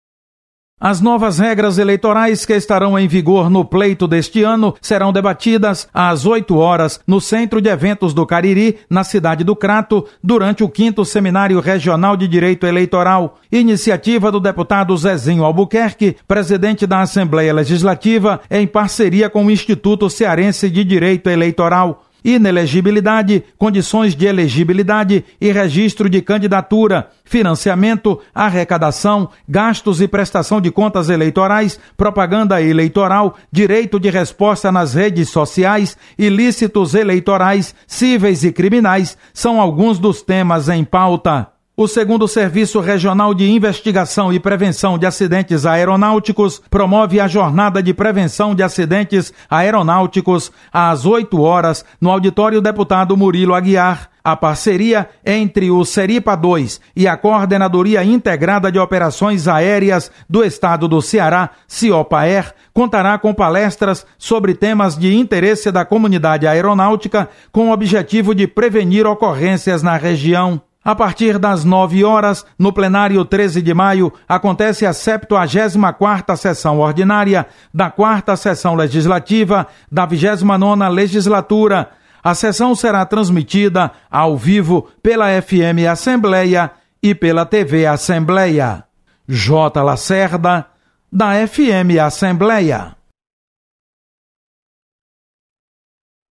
Você está aqui: Início Comunicação Rádio FM Assembleia Notícias Agenda